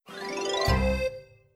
LevelCompleteSound.wav